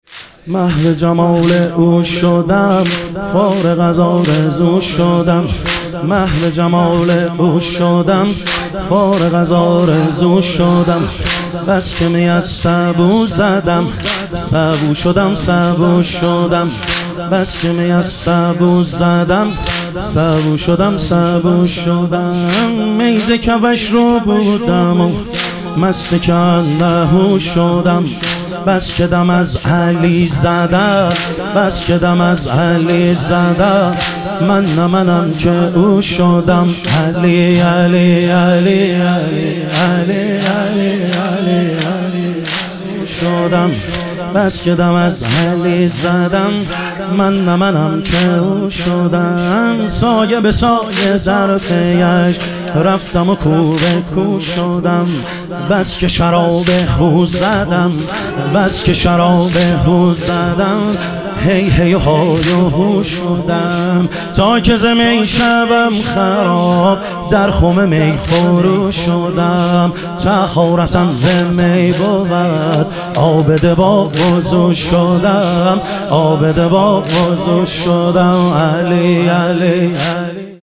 محرم الحرام - واحد